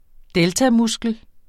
Udtale [ ˈdεlta- ]